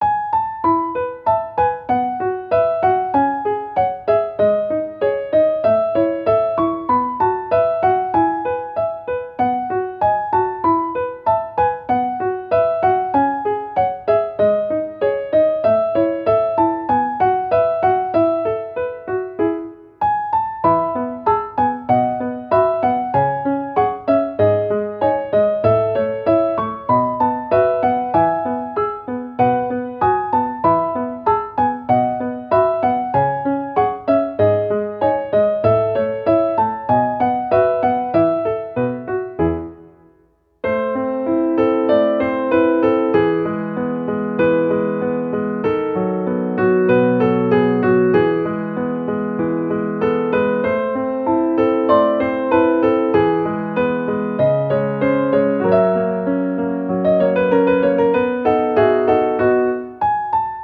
• 明るくほがらかなピアノ曲のフリー音源を公開しています。
ogg(L) - 軽やか 日常 朝 作業
ゆったりスタッカートが心地よいピアノ。